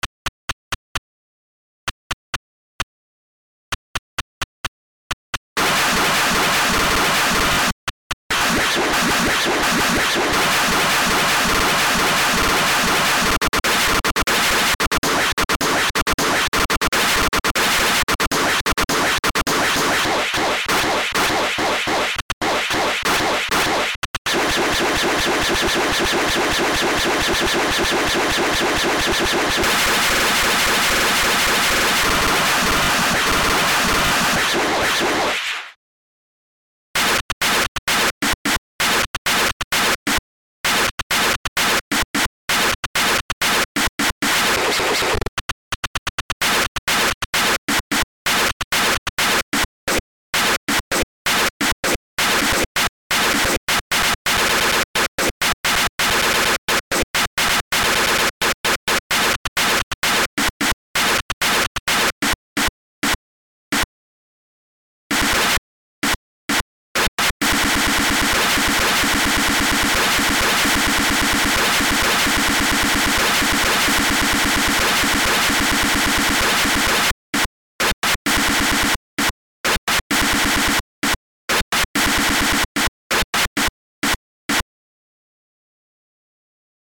weird, experimental, glitch,